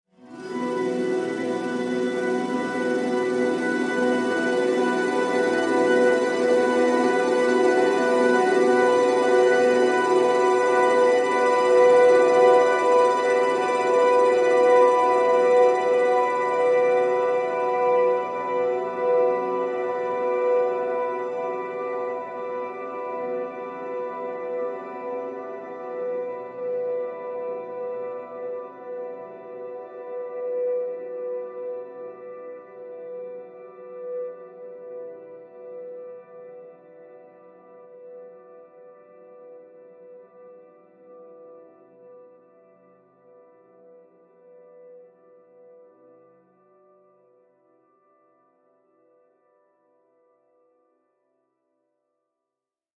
Ambiance atmosphere magic harp fantasy atmo